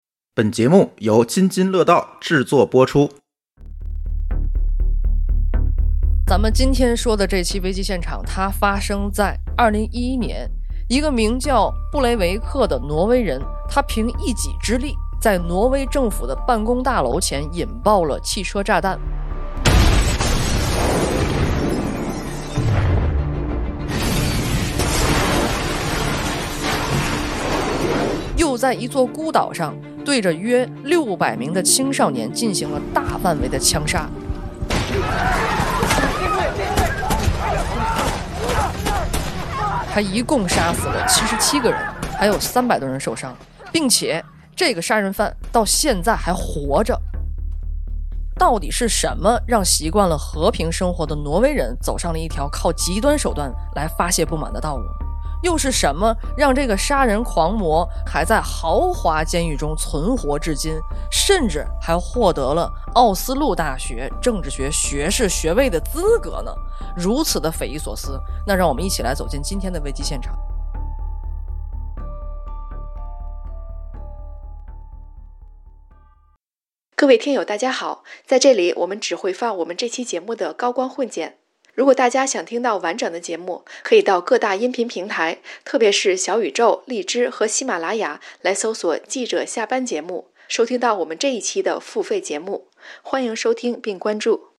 付费片花：危机现场 | 奥斯陆屠杀案—极端思想下的人性之恶 | 记者下班